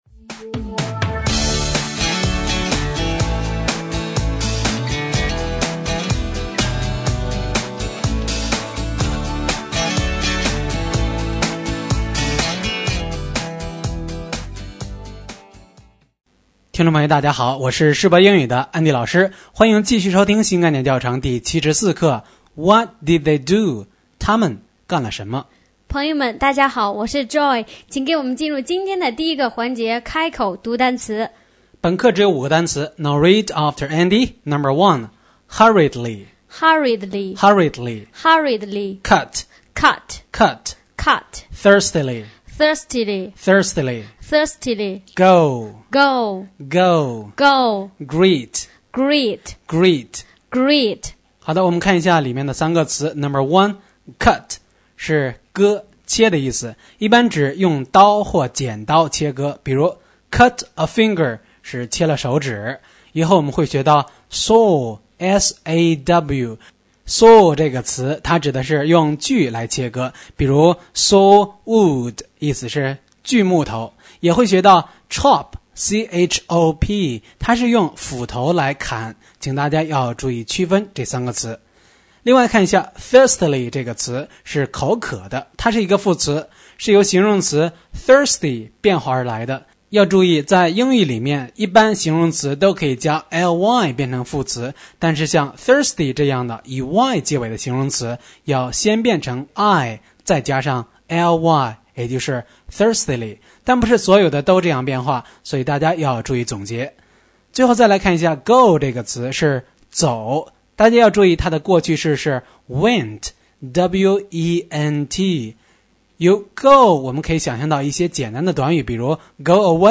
新概念英语第一册第74课【开口读单词】